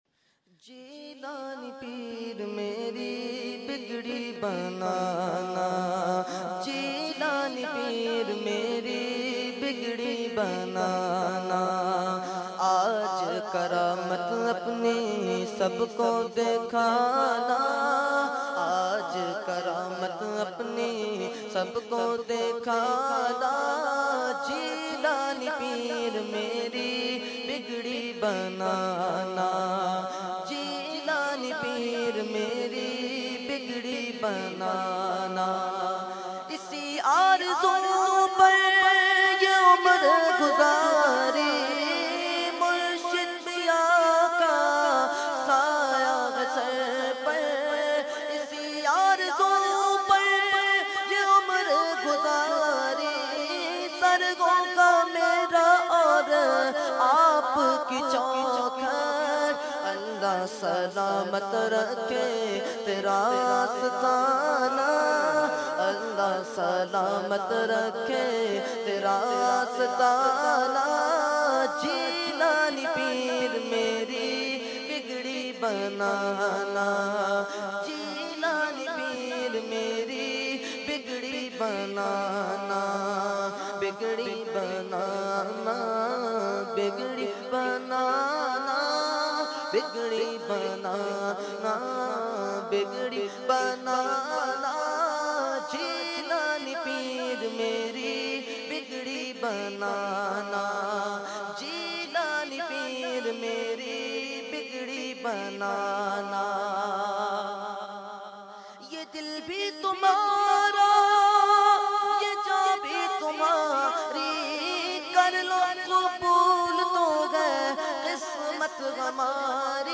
Category : Manqabat | Language : UrduEvent : Mehfil 11veen Nazimabad 22 February 2014